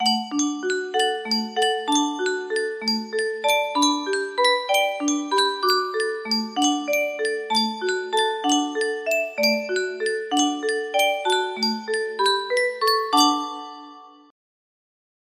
Yunsheng Music Box - O Holy Night Y079 music box melody
Full range 60